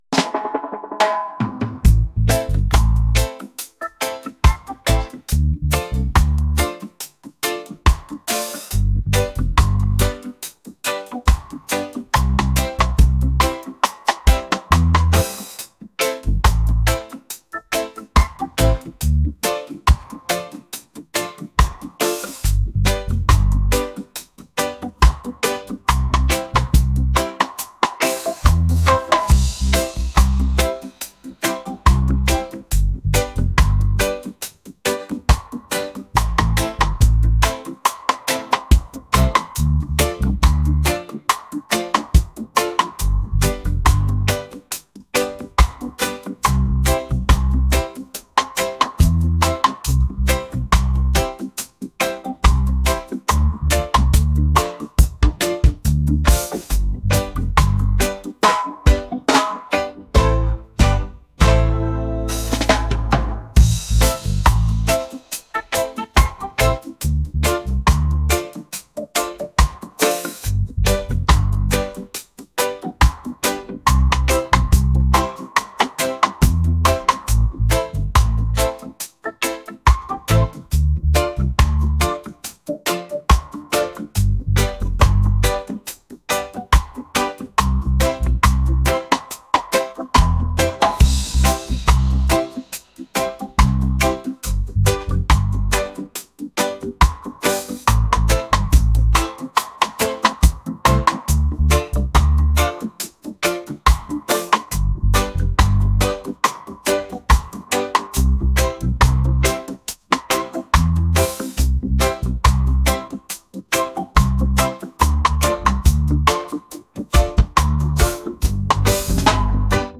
reggae | folk | acoustic